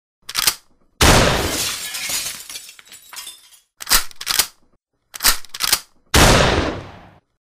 Tiếng súng Bắn qua Kính Cửa Sổ